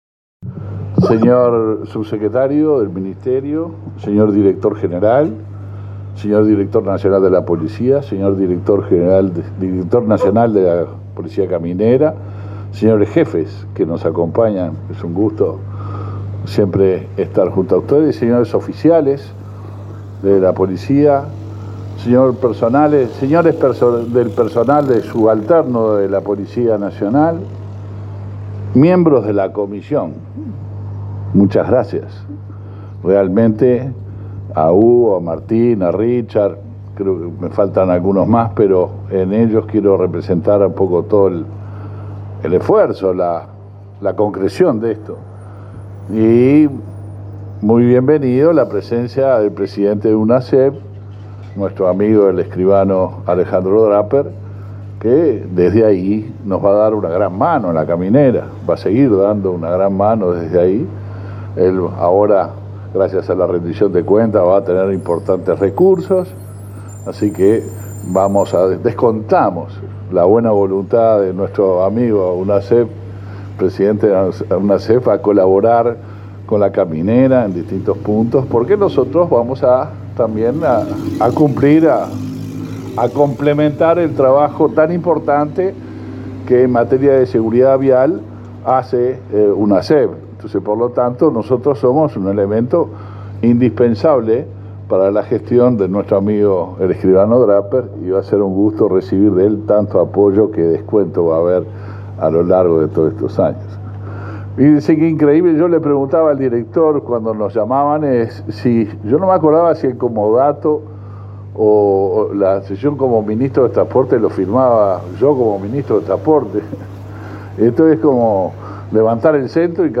Palabras del ministro del Interior, Luis Alberto Heber
El ministro del Interior, Luis Alberto Heber, participó este miércoles 15 en la inauguración de la sede de la Región I de la Dirección Nacional de